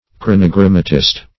Chronogrammatist \Chron`o*gram"ma*tist\, n. A writer of chronograms.